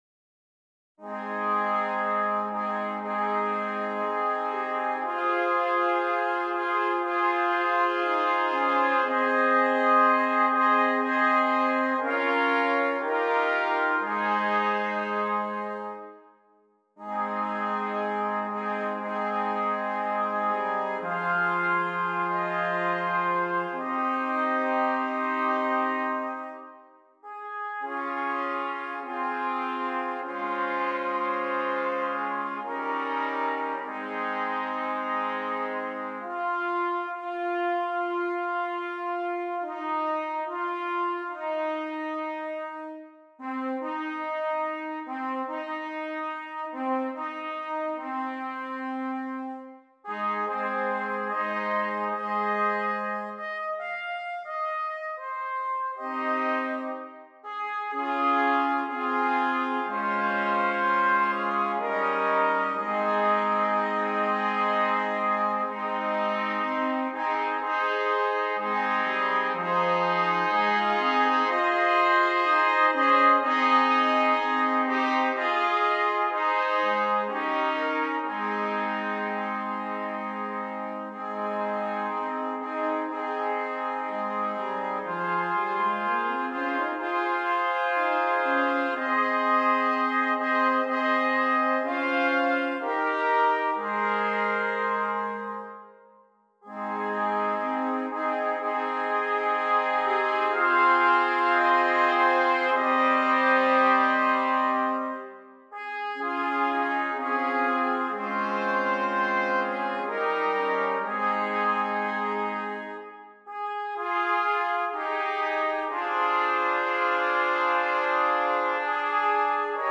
5 Trumpets